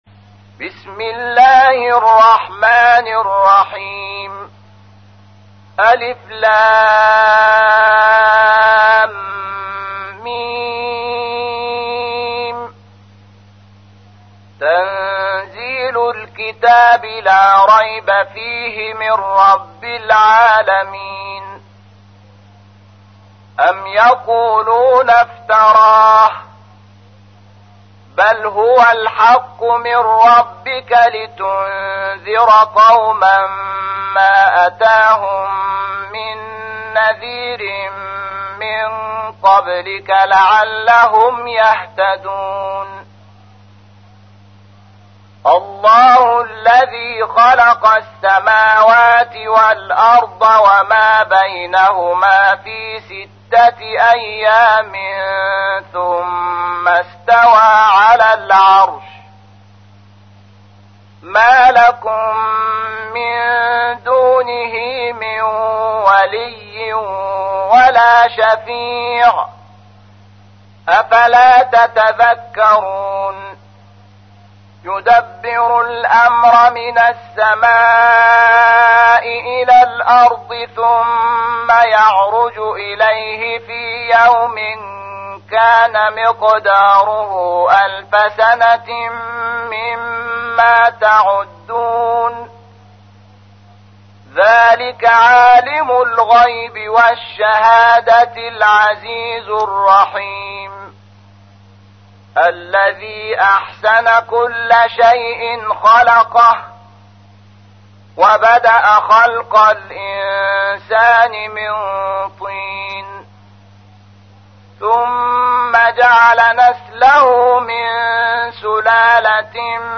تحميل : 32. سورة السجدة / القارئ شحات محمد انور / القرآن الكريم / موقع يا حسين